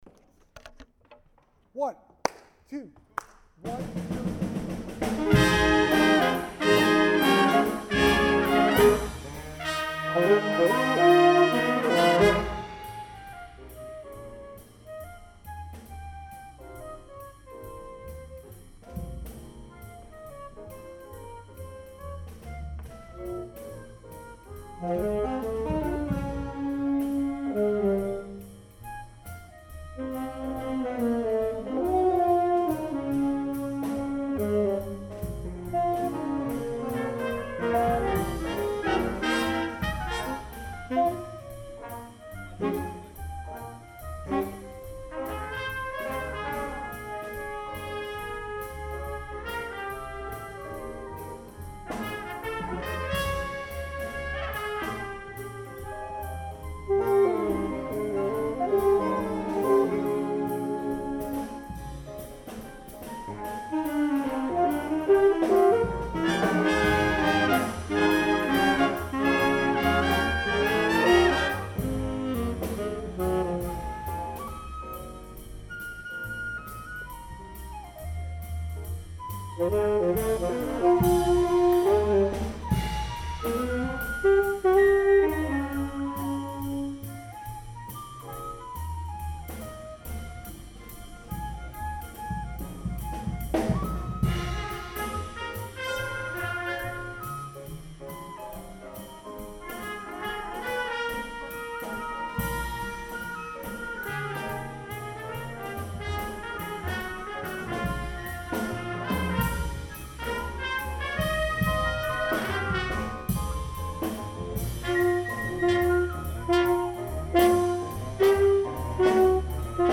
at the 2009 (19th Annual) Pulawy International Jazz Workshop
Big-Band Composition #2 recorded with a Marantz D-620 recorded at h